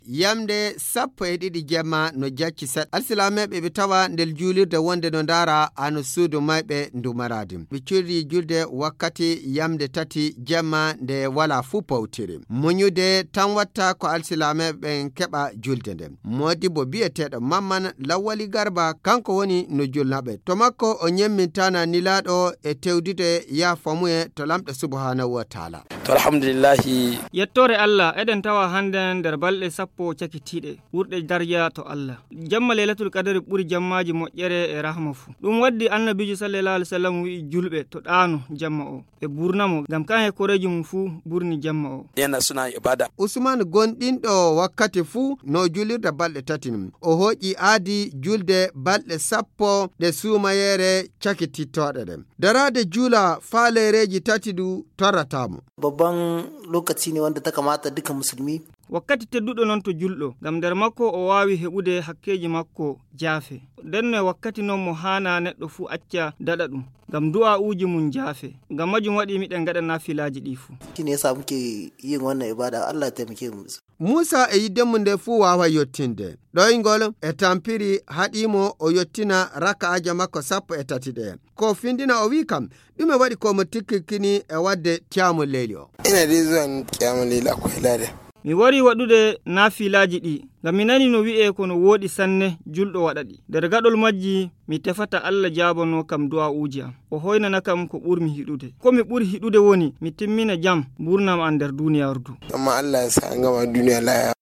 La dernière décade du mois de Ramadan est entamée depuis 3 jours. Les fidèles un peu partout investissent les mosquées à Maradi pour les prières nocturnes. Reportage